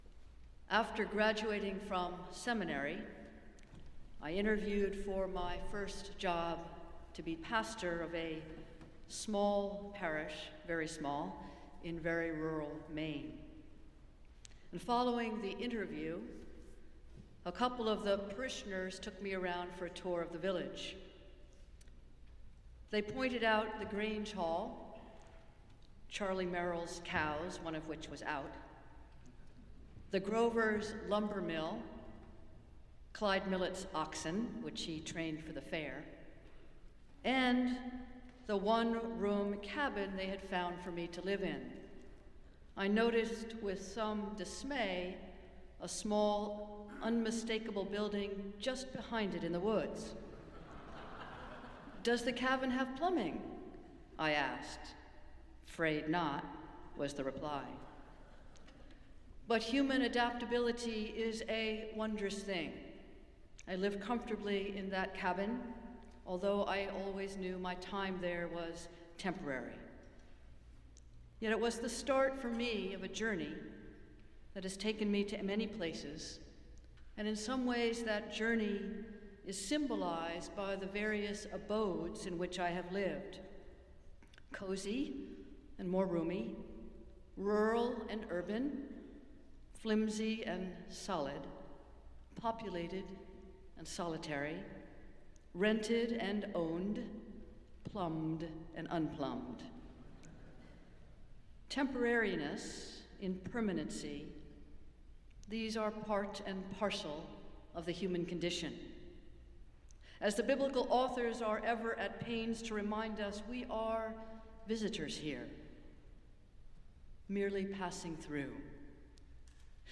Festival Worship - All-Saints' Sunday